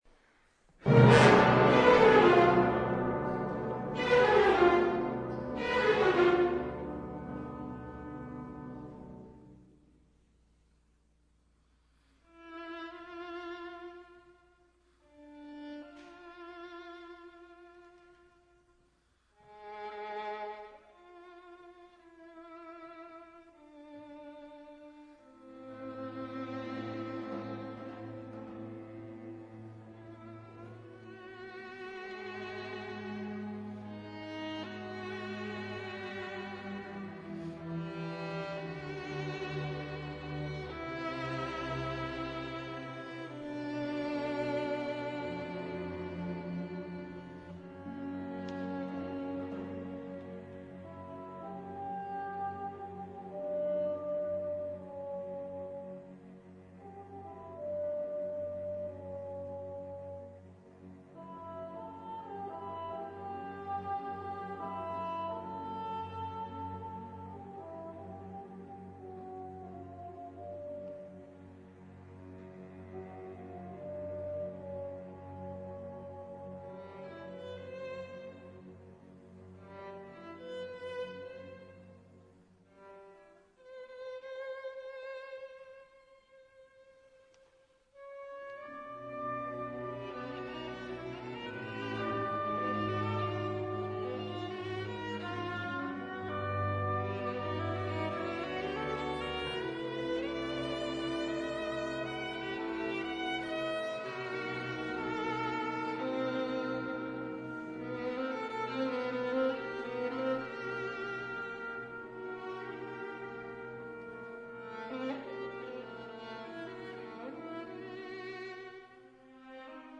Violin Solo
Timp(dTamb).Perc(3).Hp: Str